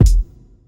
MB Kick (5).wav